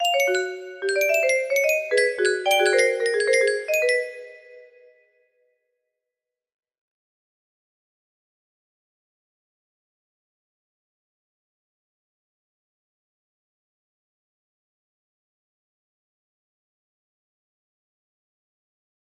Amy, I am an artist musician now music box melody